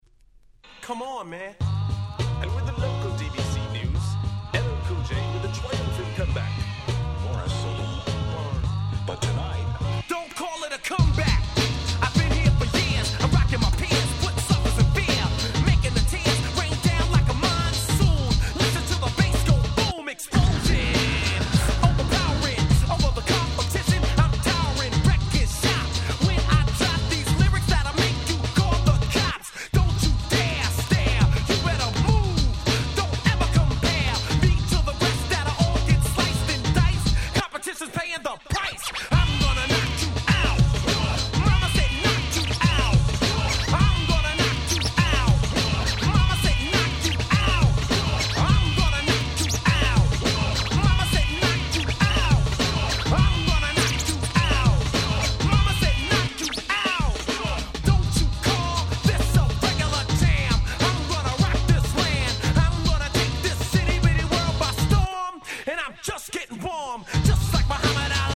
90's 80's Boom Bap ブーンバップ Old School オールドスクール